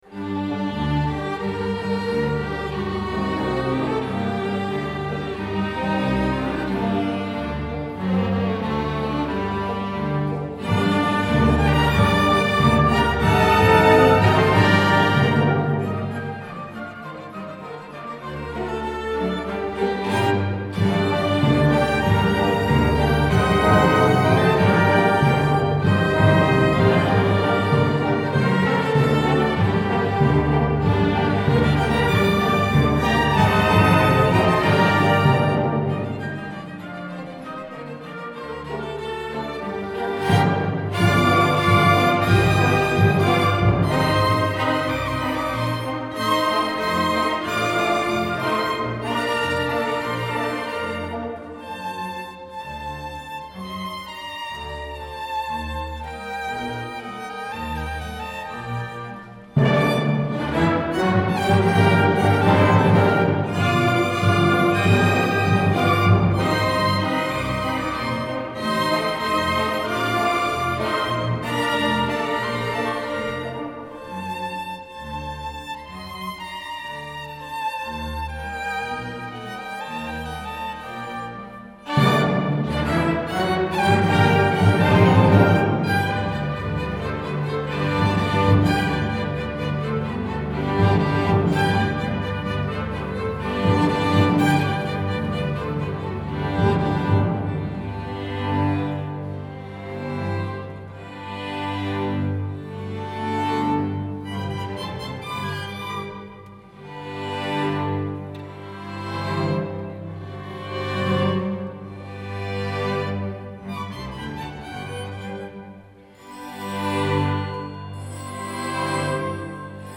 Inspelningen är gjord med en Zoom H4n Pro (24-bit/48KHz sampling)